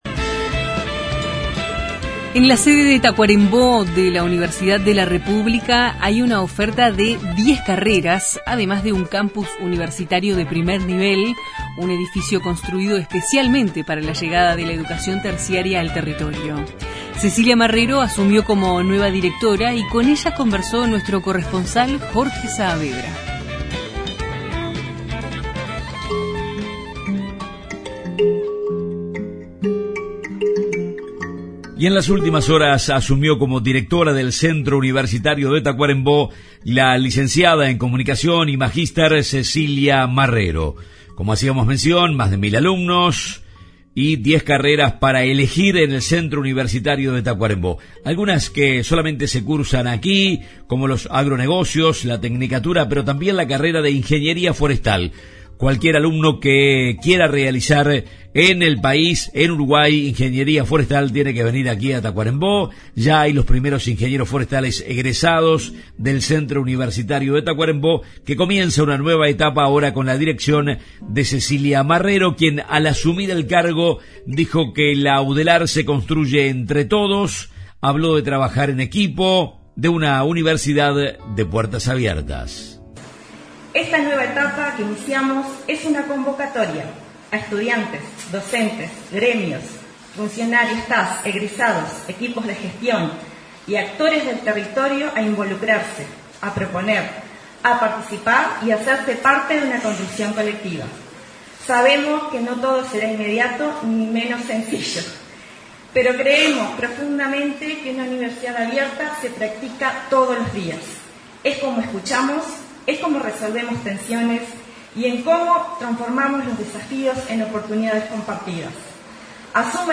Los informes de nuestros corresponsales en Tacuarembó, Cerro Largo y Paysandú.